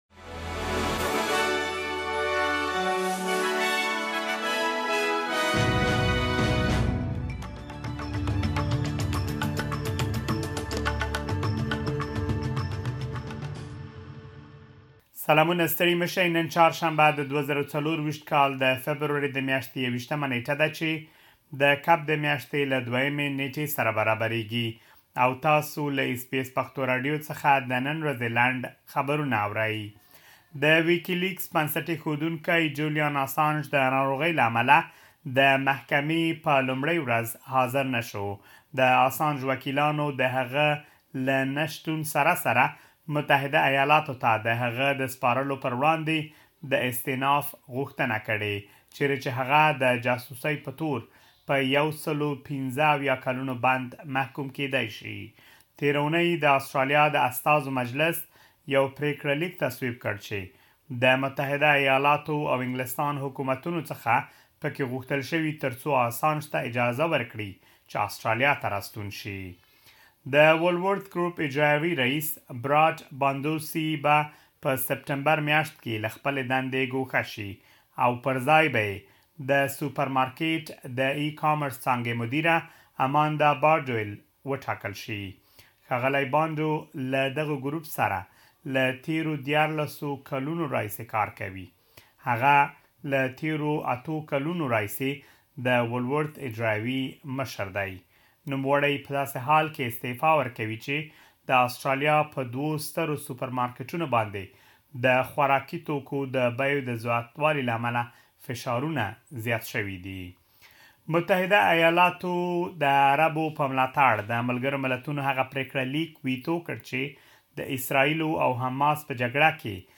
د اس بي اس پښتو راډیو د نن ورځې لنډ خبرونه |۲۱ فبروري ۲۰۲۴
د اس بي اس پښتو راډیو د نن ورځې لنډ خبرونه دلته واورئ